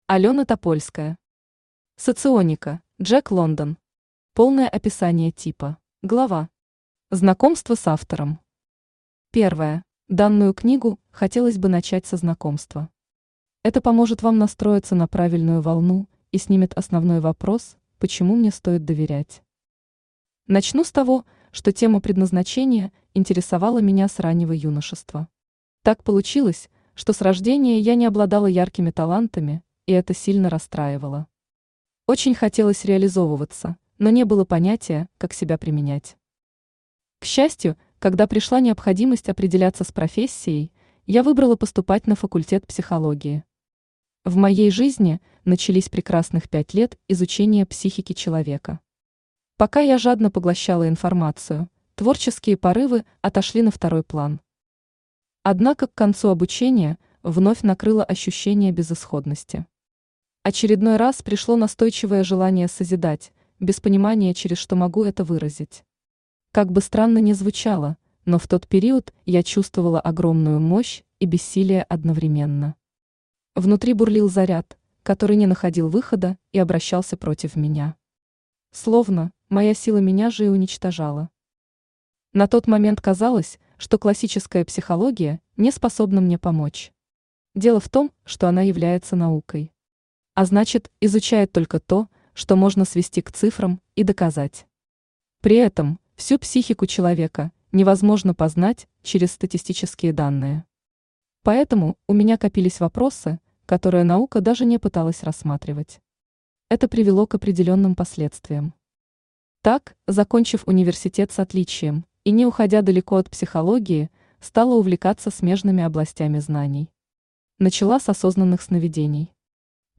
Читает аудиокнигу Авточтец ЛитРес.